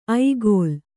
♪ aigōl